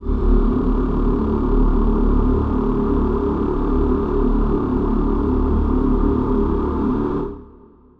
Choir Piano